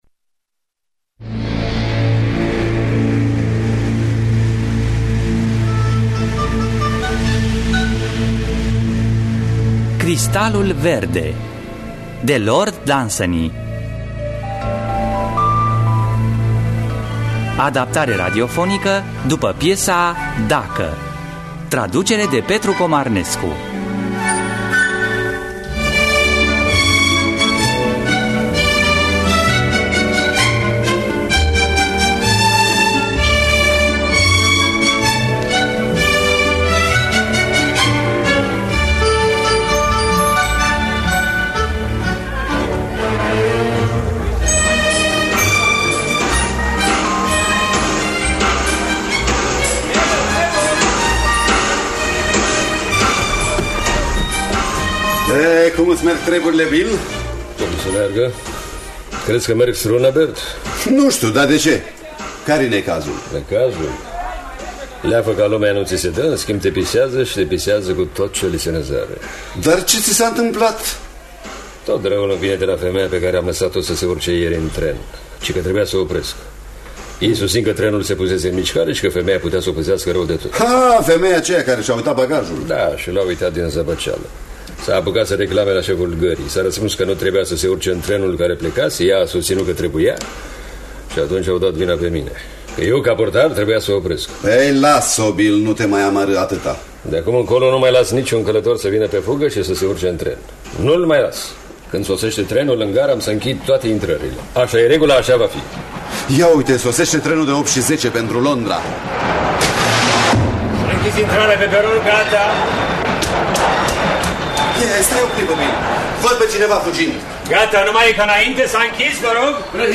Cristalul verde sau Dacă de Lord Dunsany – Teatru Radiofonic Online
Înregistrare din anul 1996.